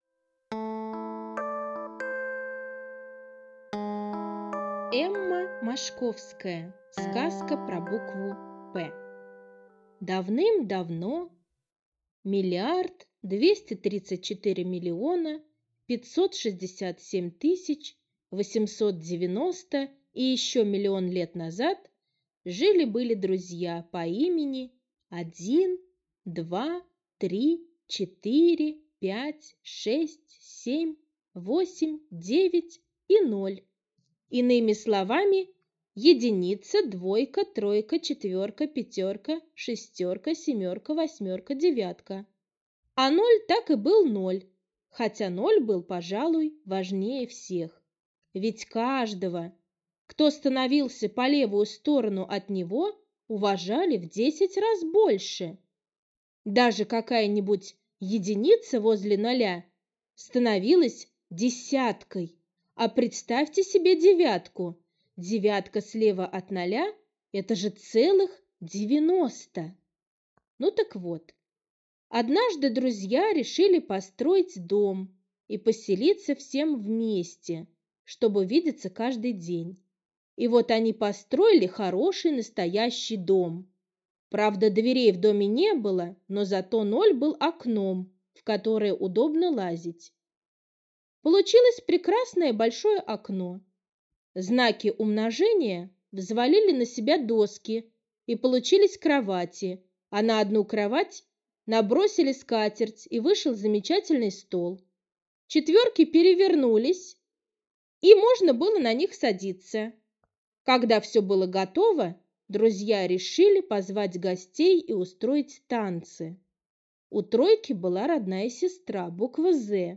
Сказка про букву П - аудиосказка Мошковской Э.Э. Сказка про все цифры и буквы, которые решили построить дом и поселиться всем вместе.